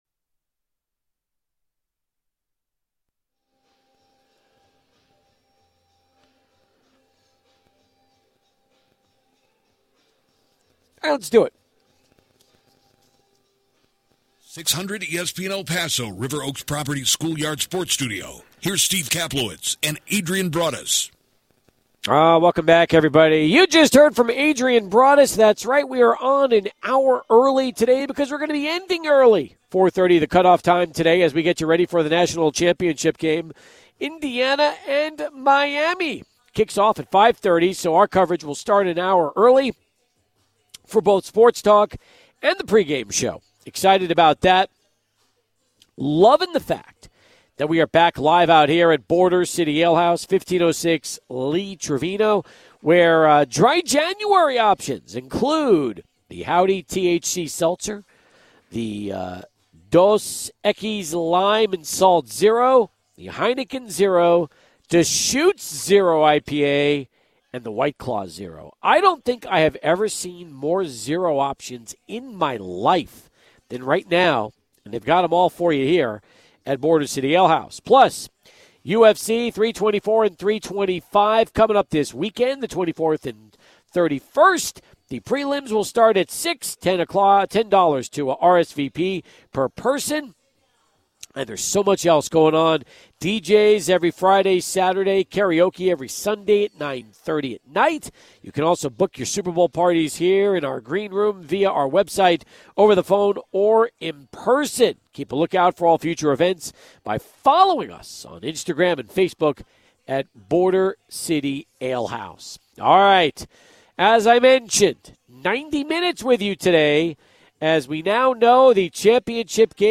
SportsTalk Monday, January 19: Border City Alehouse